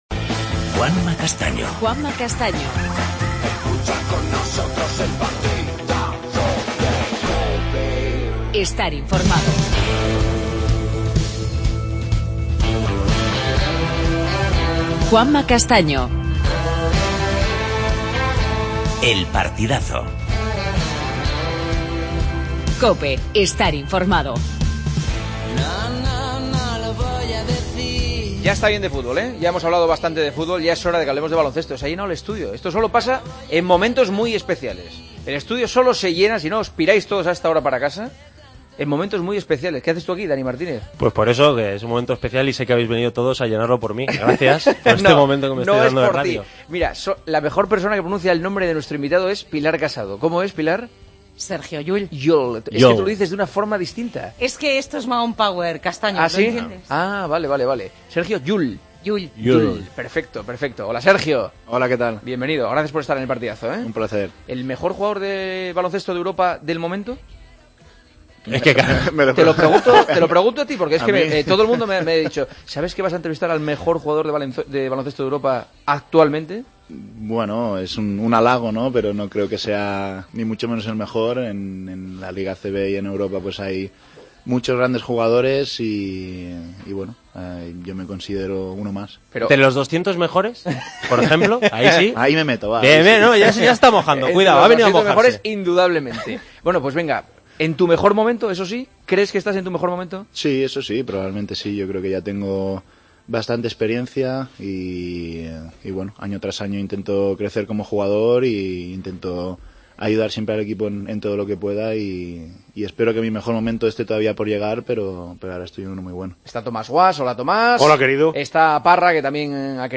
AUDIO: Entrevista a Sergio Llull. La agenda del día.